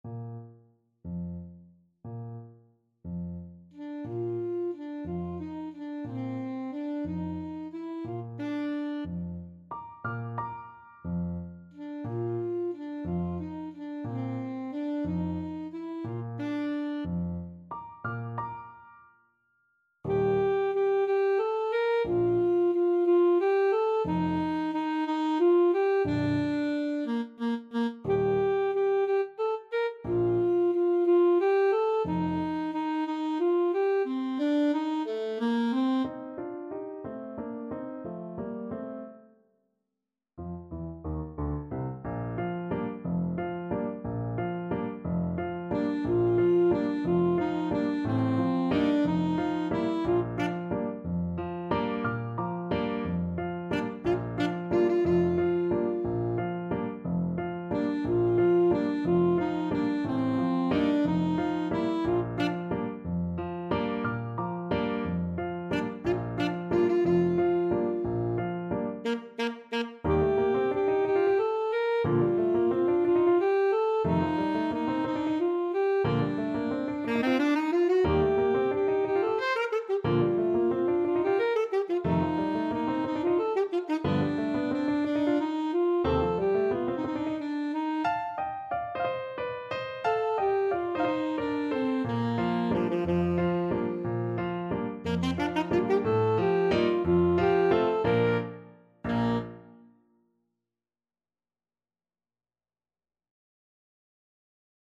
Alto Saxophone
6/8 (View more 6/8 Music)
Classical (View more Classical Saxophone Music)